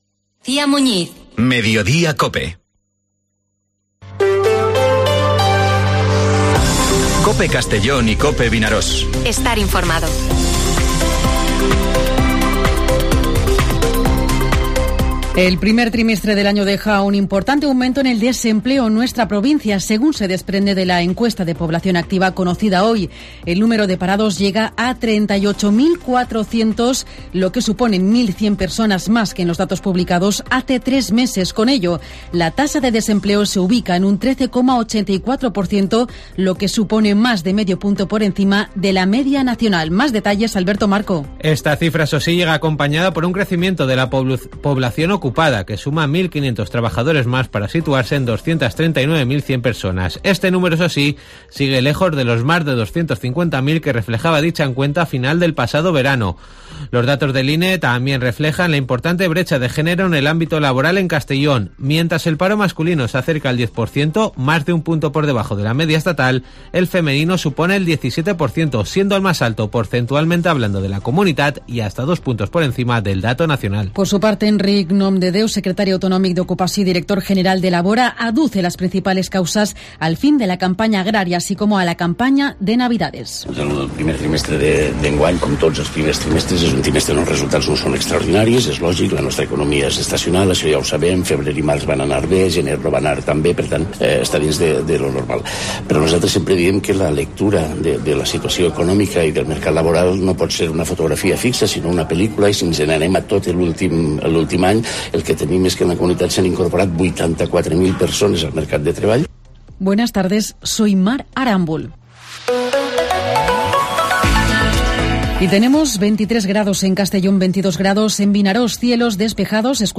Informativo Mediodía COPE en Castellón (27/04/2023)